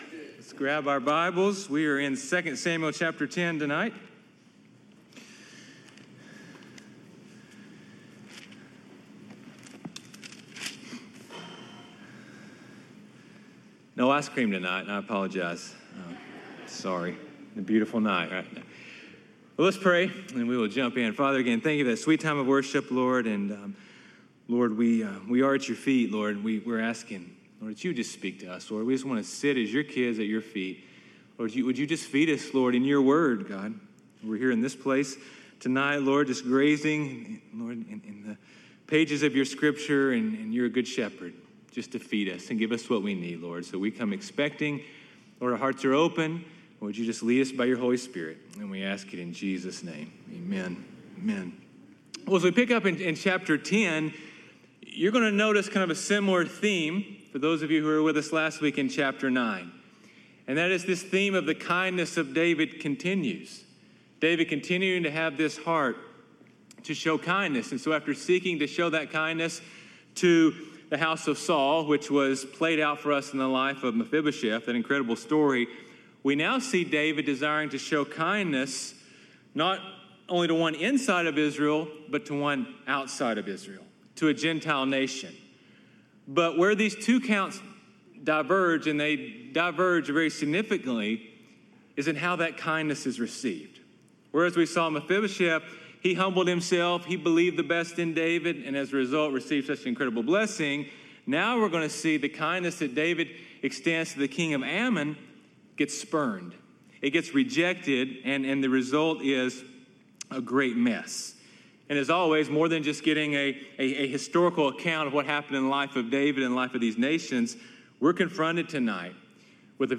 sermons 2 Samuel 10:1-19 | Kindness Spurned